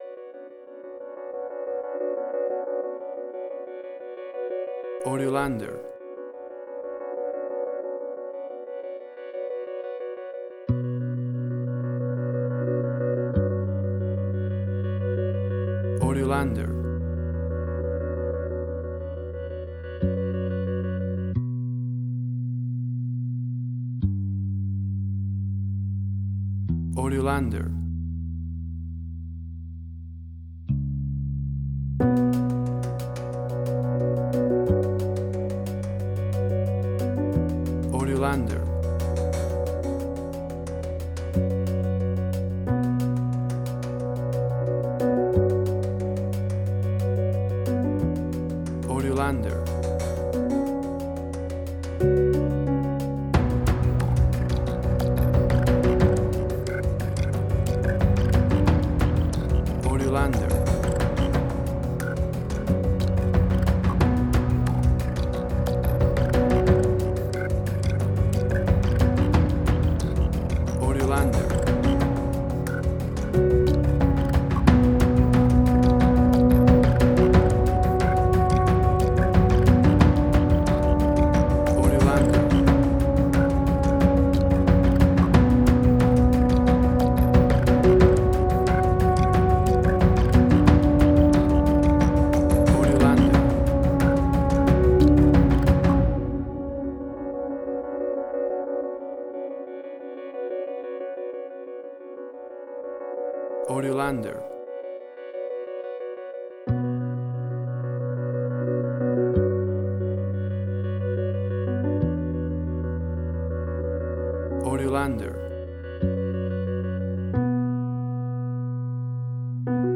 Airy industrial tension music.
Tempo (BPM): 90